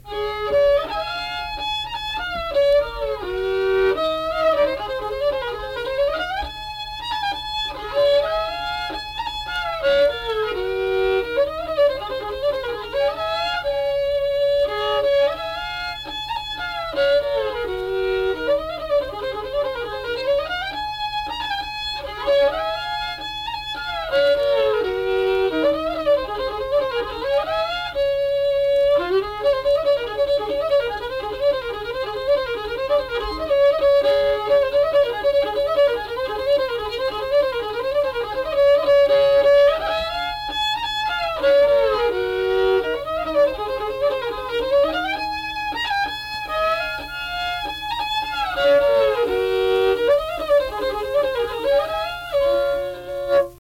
Unaccompanied fiddle music and accompanied (guitar) vocal music
Verse-refrain 2(2).
Instrumental Music
Fiddle
Braxton County (W. Va.)